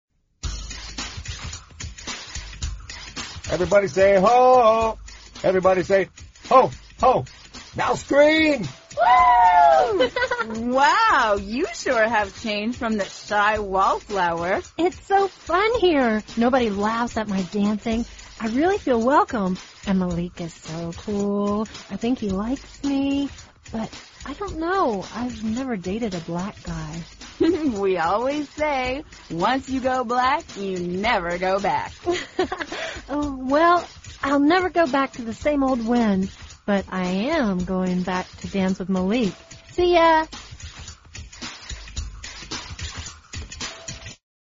位置：首页 > 英语听力 > 美语|美国英语|美式英语 > 美语会话实录